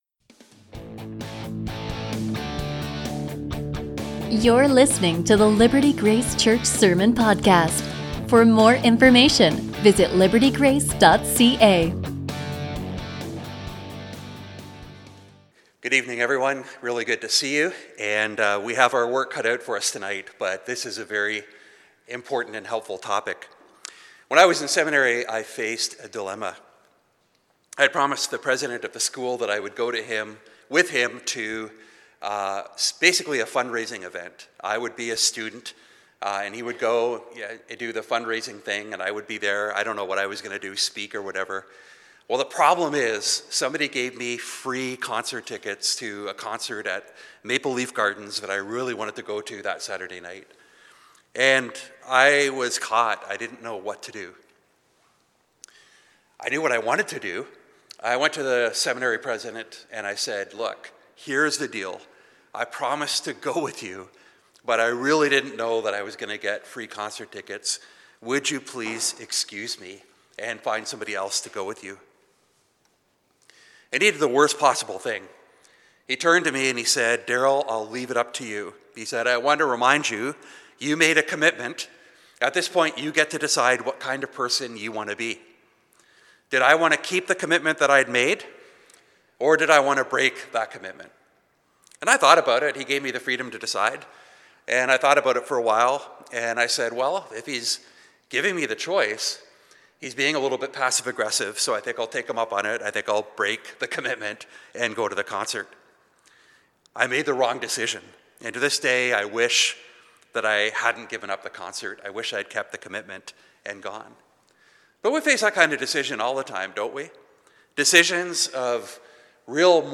A sermon from 1 Corinthians 10